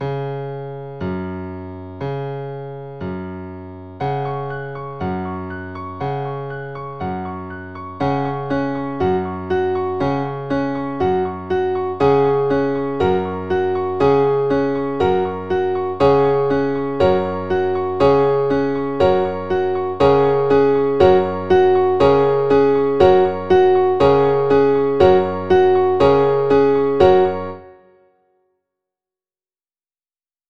quick and easy piano ensembles for small to large groups